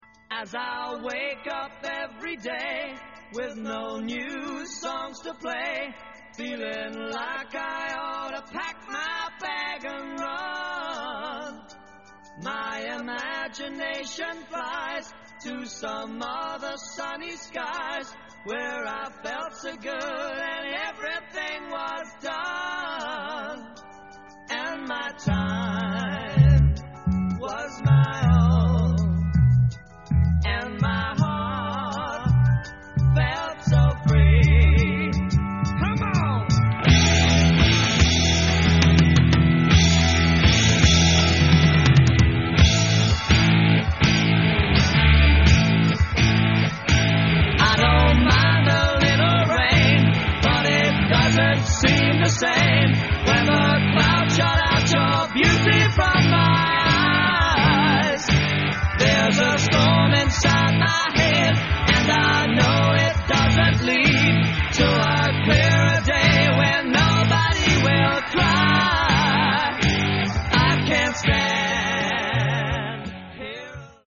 Lansdowne Studios, London
Organ, Piano, Guitar & Vocal
Drums